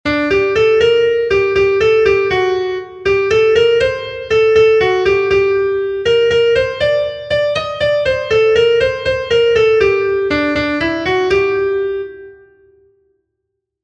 A-B-C